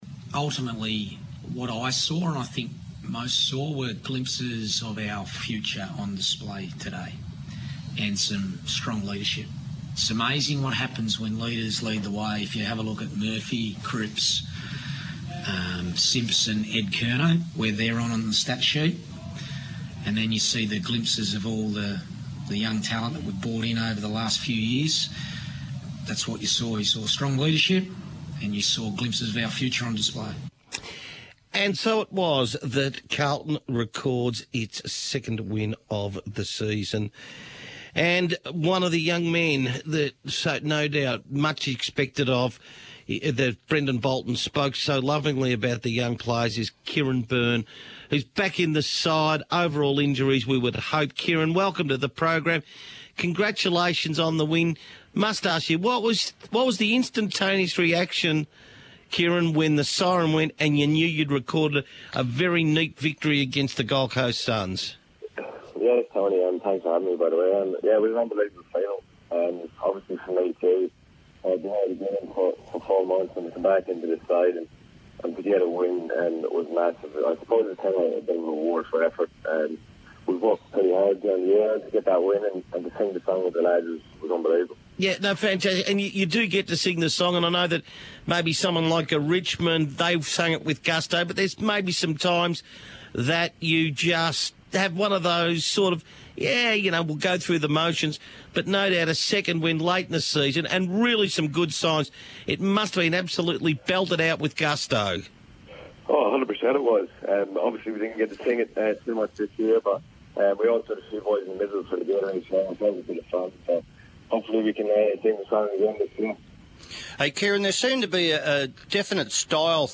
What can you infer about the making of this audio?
speaks to Macquarie Sports Radio after returning from injury in the Blues' victory over the Suns.